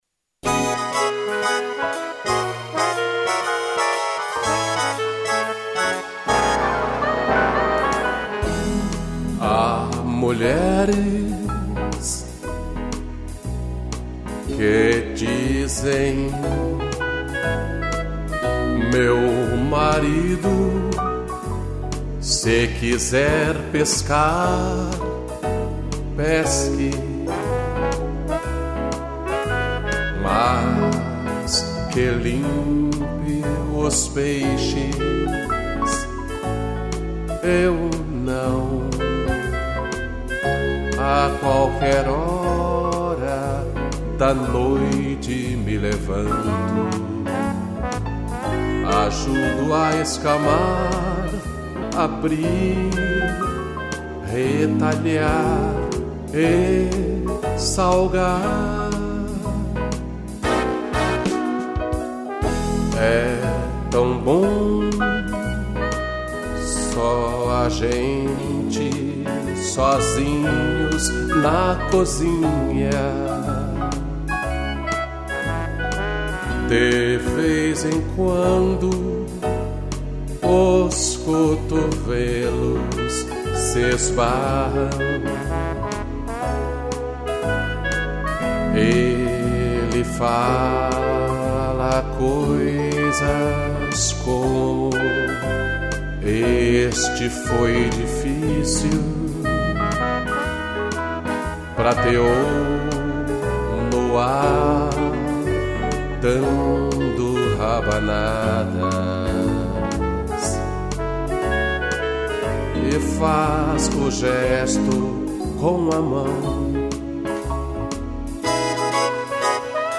voz
piano, trombone e clarinete